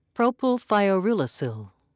(proe-pill-thye-oh-yoor'a-sill)